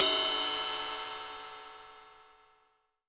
Index of /90_sSampleCDs/AKAI S6000 CD-ROM - Volume 3/Ride_Cymbal1/18INCH_ZIL_RIDE